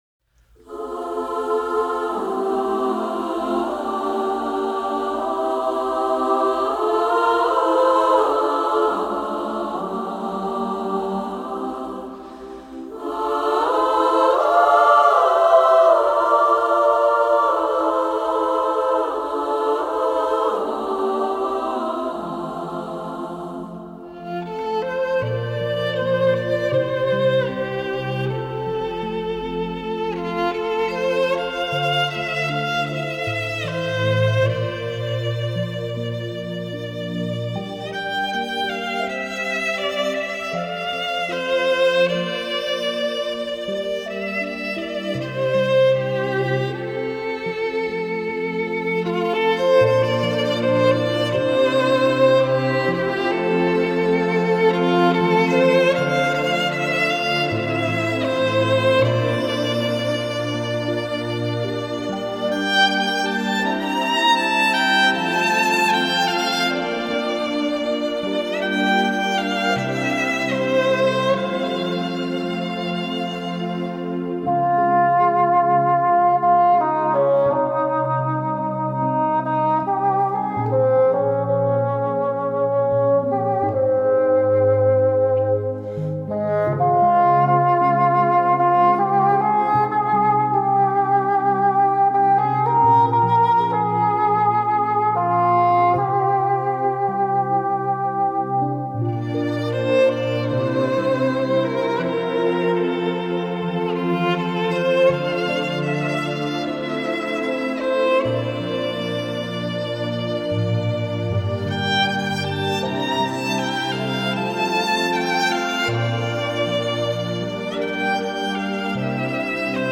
radiant ensemble voices